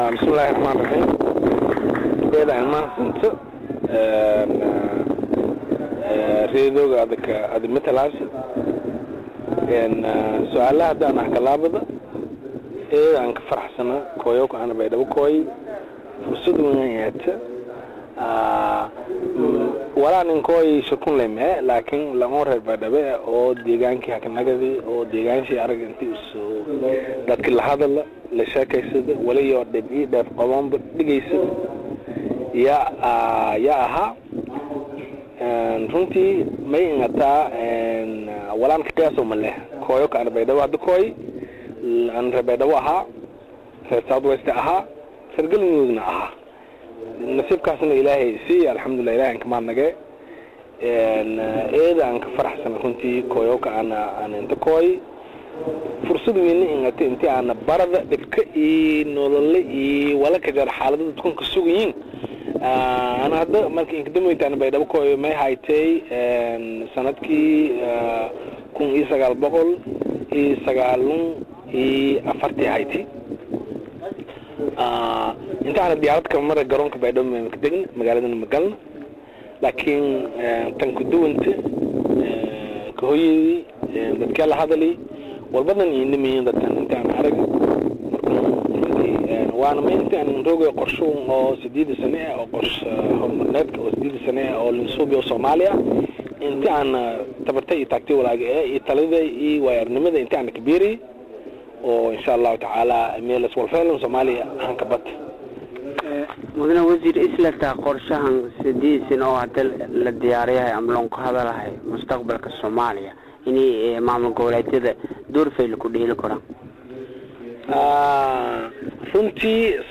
Dhageyso: Wasiirka Iskaashiga Caalamiga Ee Gal-Mudug Oo Ka Hadlaya Shirka Qorshaha Qaranka Ee Baydhabo Ka Furmey
Wasiirka oo ka hadley Luuqada Maayga oo aad u la liyaabeyso sida u ugu hadalayo, isagoona sheegey in dhoor wasiiro ka tirsan Galmudug ay ku hadlaan Maay-ga, waxa uu dadka kula taliyey in horumariyo Mayga.